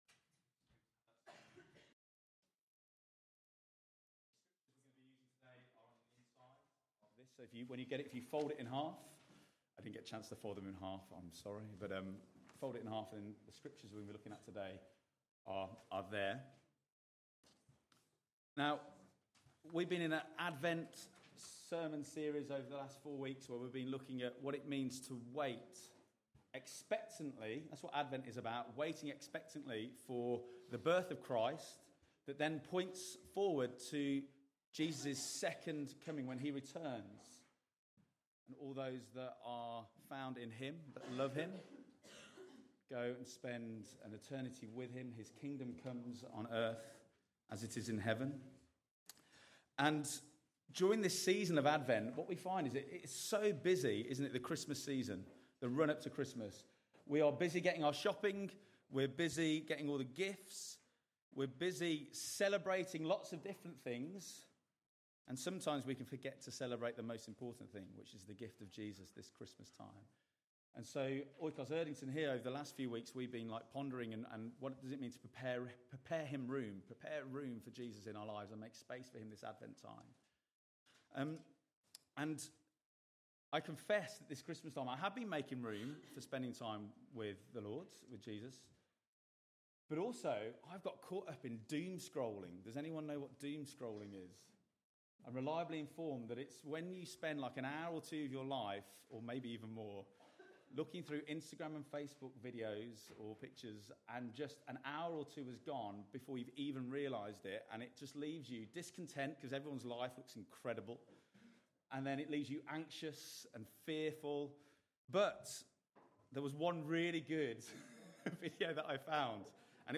Sermons by OIKOS Church